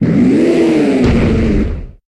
Cri de Boumata dans Pokémon HOME.